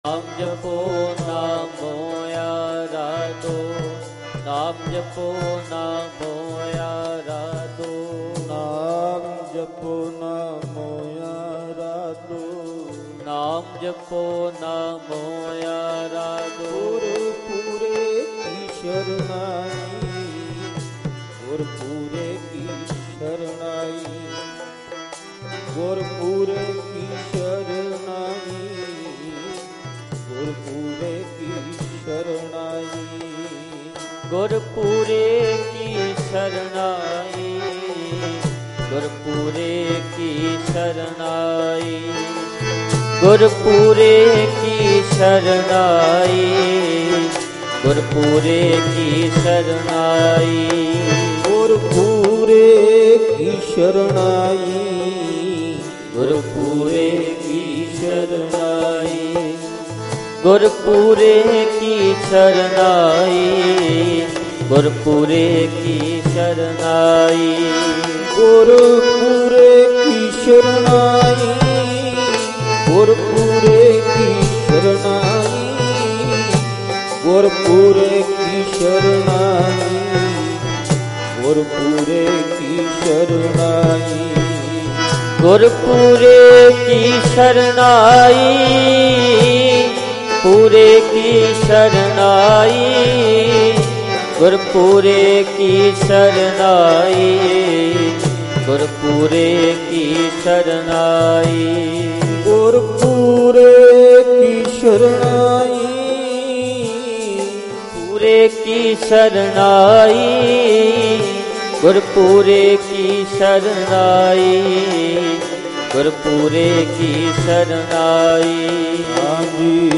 LIVE USA